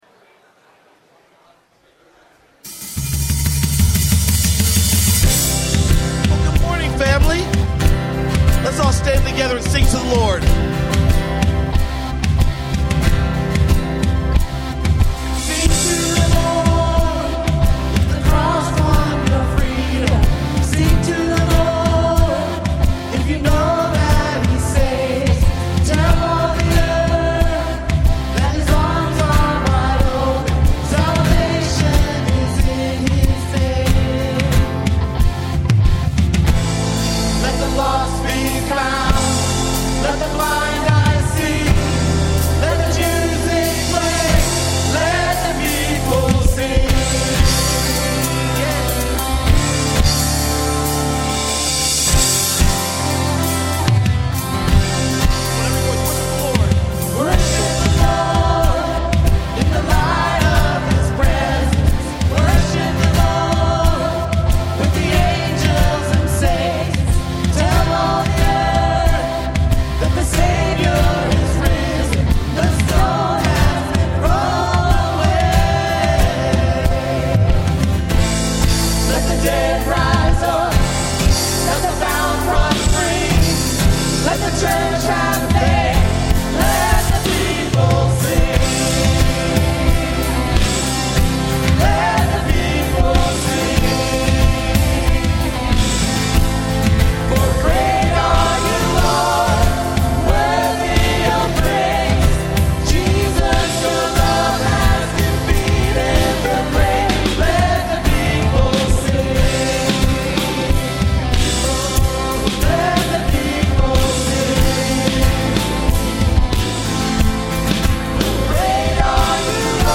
A message from the series "Redeeming Love."